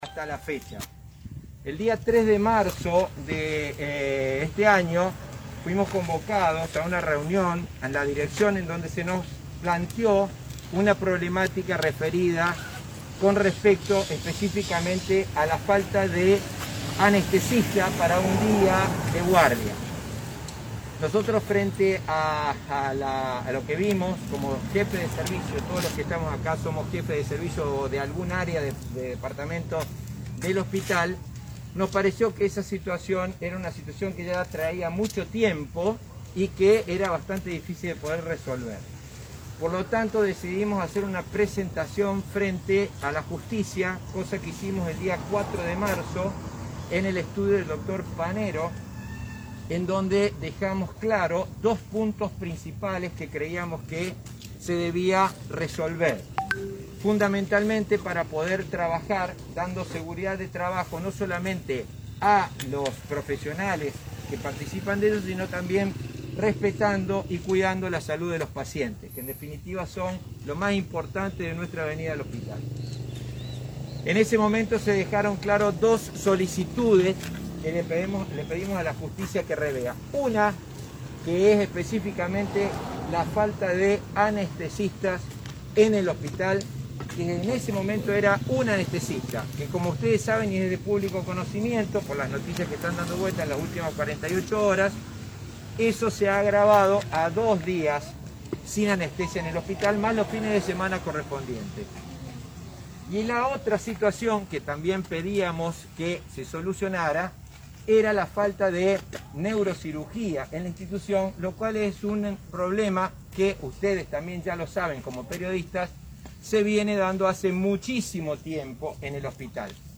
Jefes y referentes de Servicio del Hospital “J. B. Iturraspe” explicaron en conferencia de prensa la situación crítica que están atravesando frente a urgencias. En este marco, los profesionales hicieron referencia a la falta de recursos humanos y que presentaron una denuncia en la Justicia por falta de anestesistas y neurocirujanos.